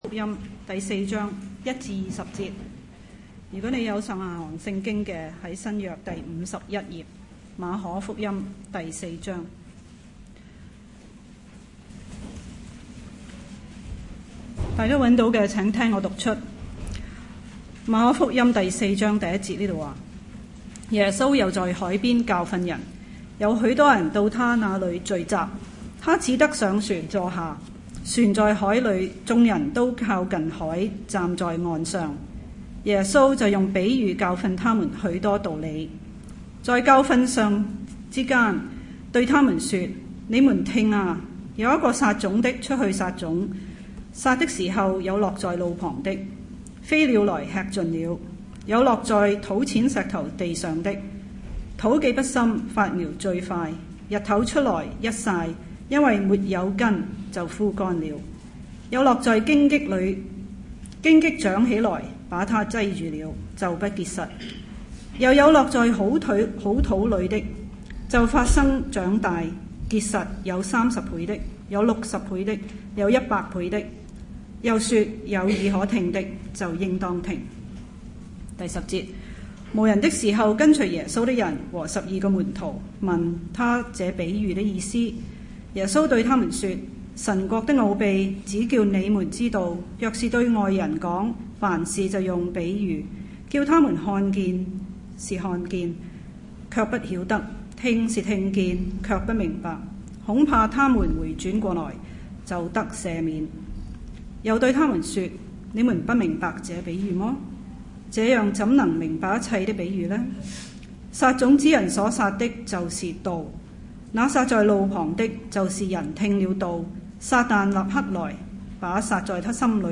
差传主日